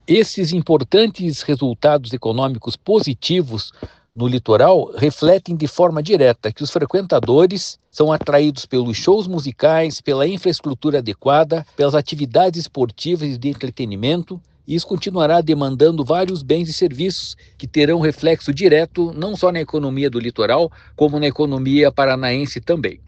Sonora do diretor-presidente do Ipardes, Jorge Callado, sobre os efeitos econômicos positivos do aumento do fluxo turístico no Litoral